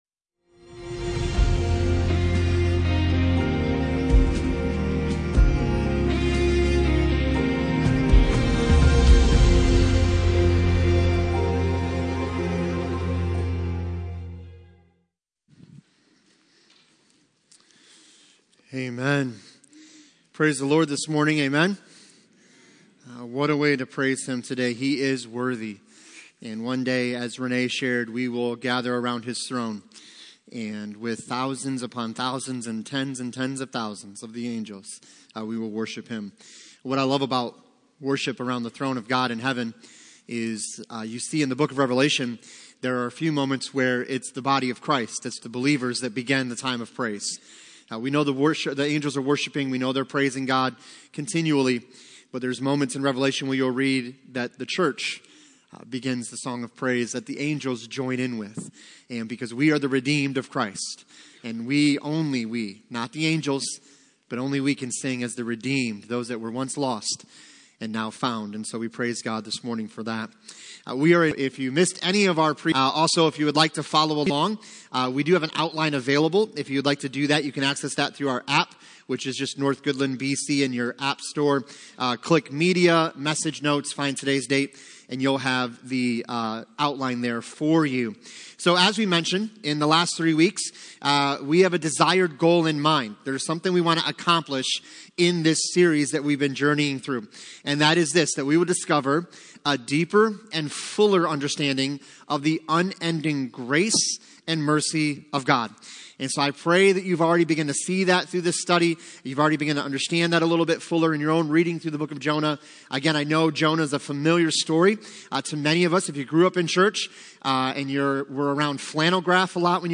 Passage: Jonah 3:1-3 Service Type: Sunday Morning